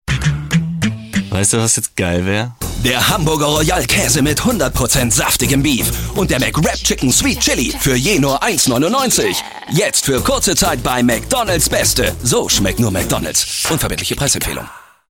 *Der Werbespot wurde über unser Vermarkter Gebucht.